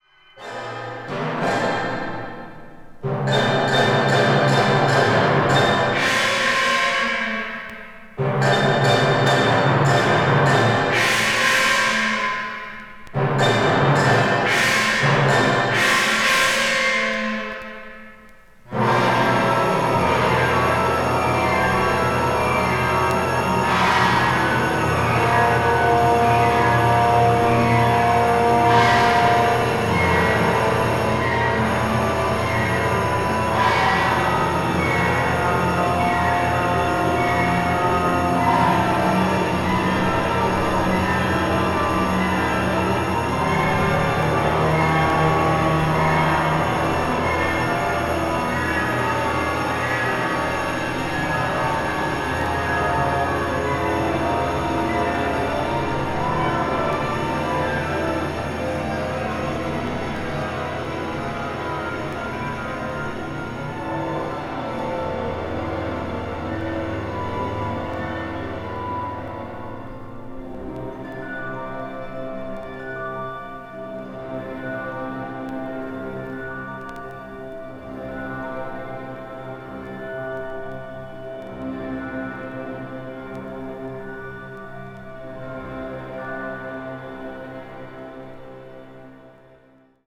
media : EX-/EX-(わずかにチリノイズが入る箇所あり,軽いプチノイズ数回あり)